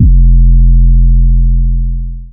DDW3 808 3.wav